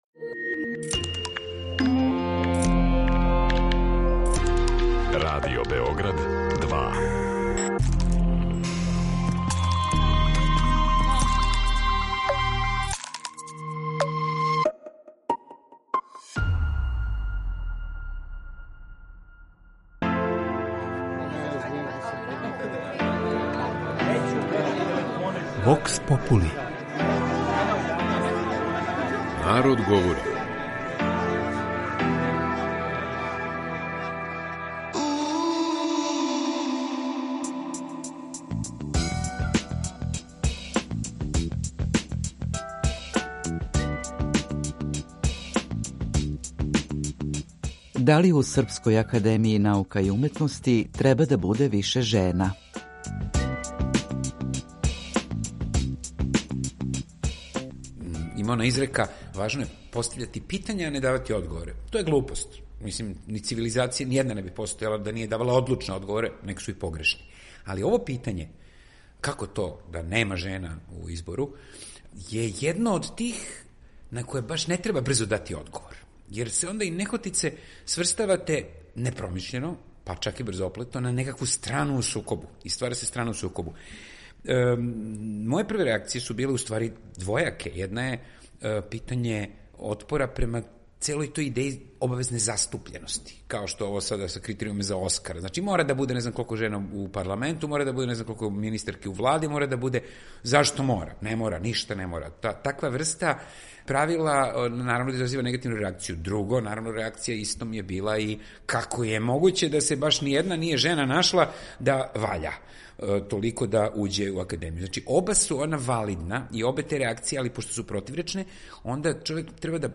Вокс попули
Ми смо питали наше суграђане шта они мисле о овој деликатној теми.